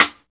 Index of /90_sSampleCDs/Roland - Rhythm Section/SNR_Rim & Stick/SNR_Stik Modules
SNR PAPER 0J.wav